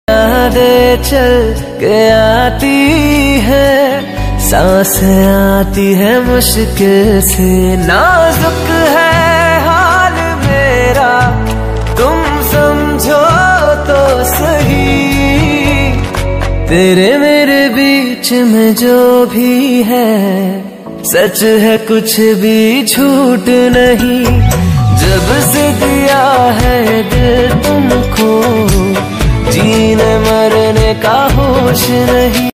New Sad song Ringtone